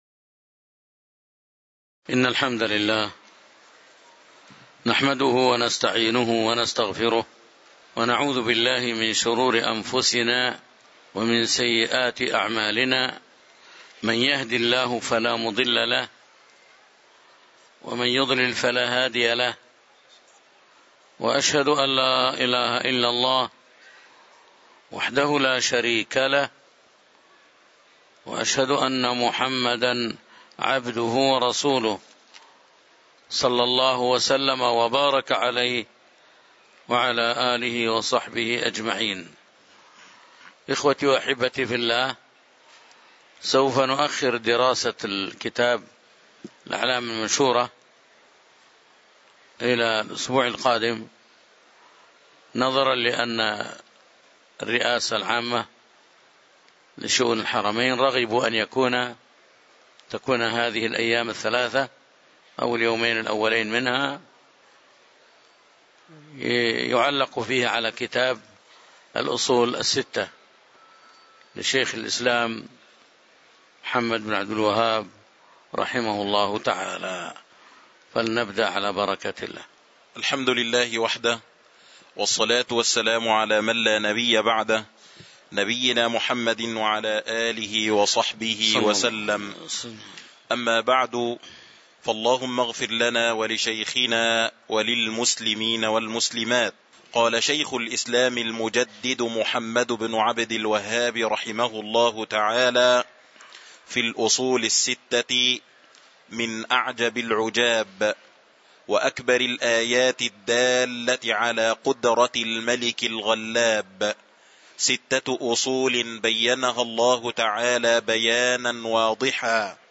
تاريخ النشر ١٩ محرم ١٤٤٥ هـ المكان: المسجد النبوي الشيخ